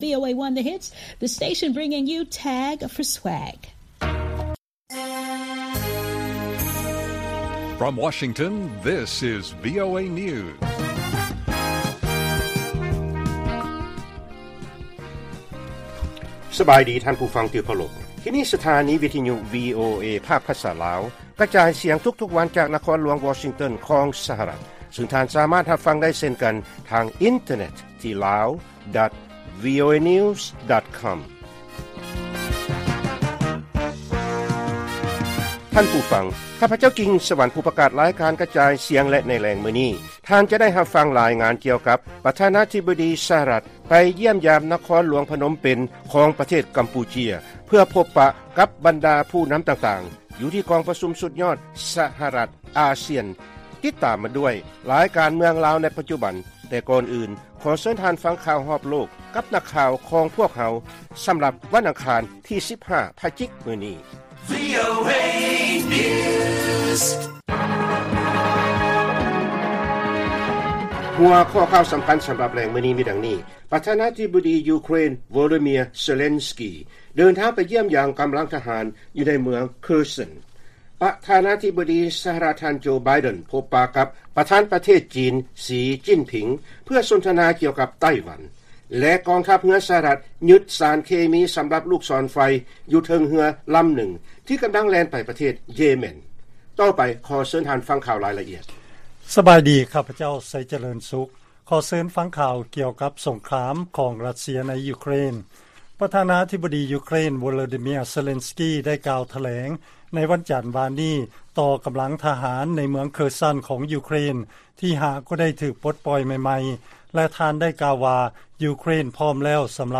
ລາຍການກະຈາຍສຽງຂອງວີໂອເອ ລາວ: ປະທານາທິບໍດີ ຢູເຄຣນ ໂວໂລດີເມຍ ເຊເລັນສກີ ເດີນທາງໄປຢ້ຽມຢາມ ກຳລັງທະຫານ ຢູ່ໃນເມືອງເຄີສັນ